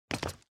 move01.mp3